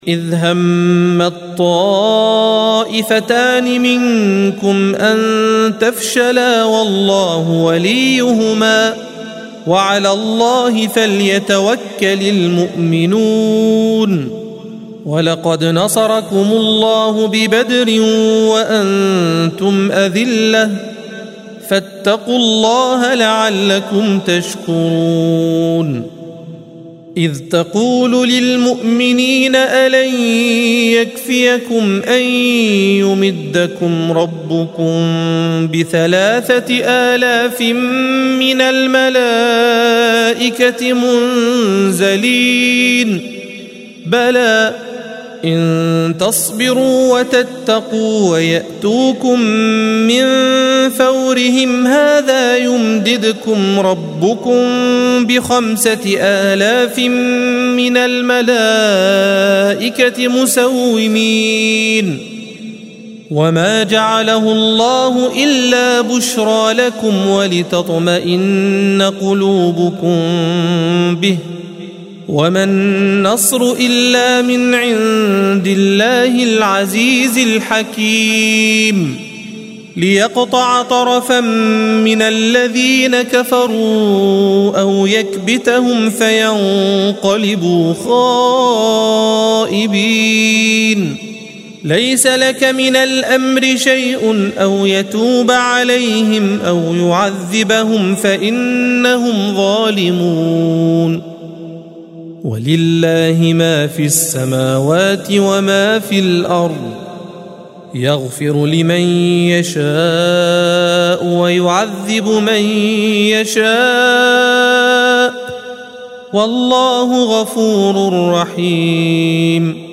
الصفحة 66 - القارئ